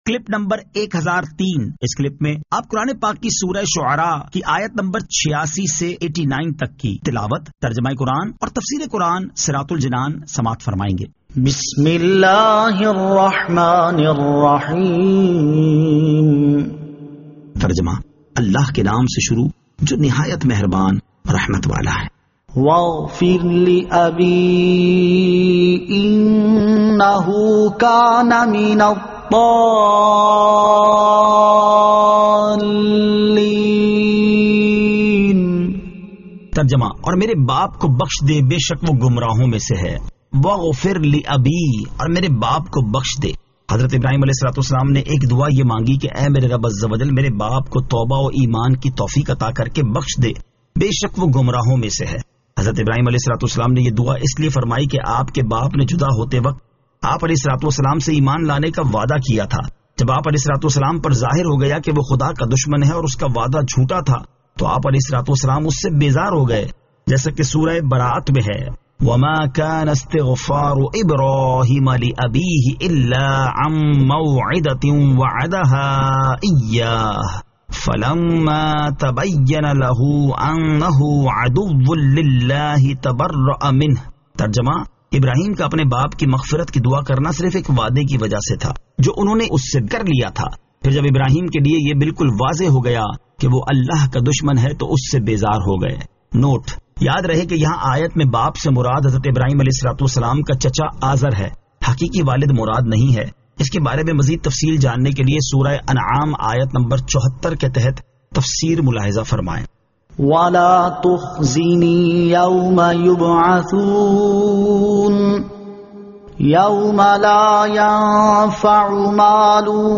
Surah Ash-Shu'ara 86 To 89 Tilawat , Tarjama , Tafseer